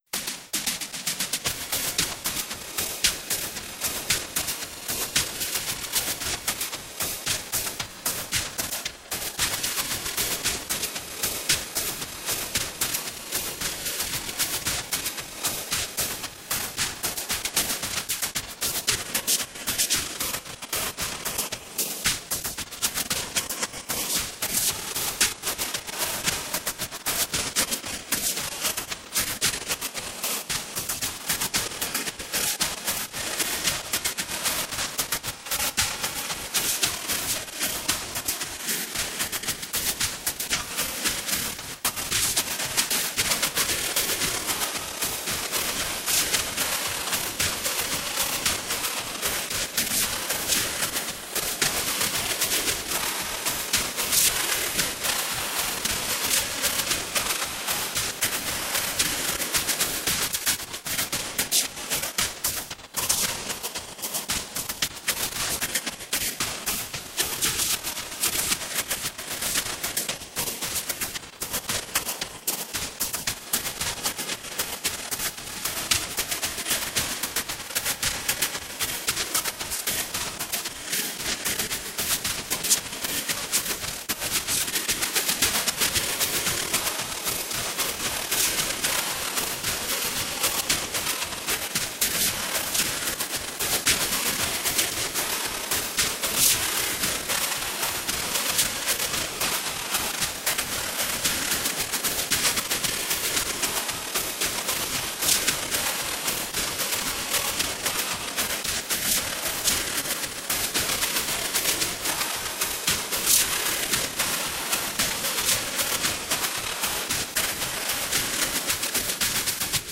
The song is definitely recognizable, but heavily distorted.